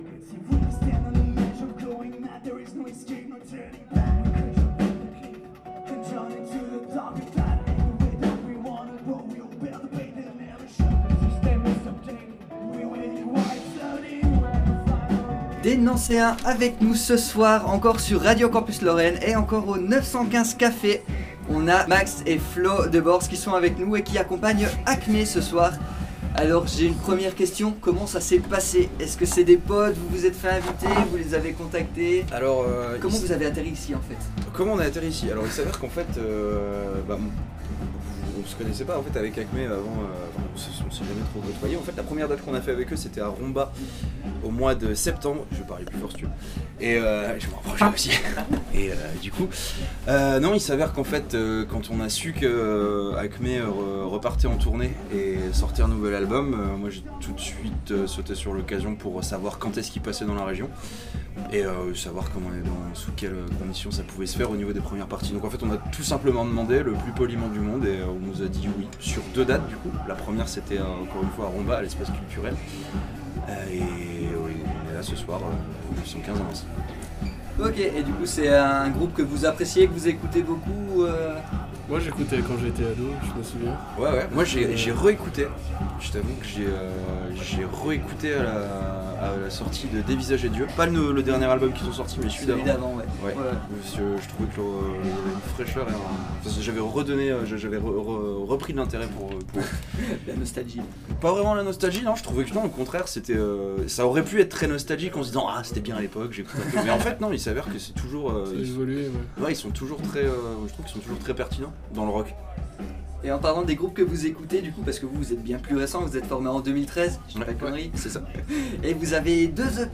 interview-boars-montee.mp3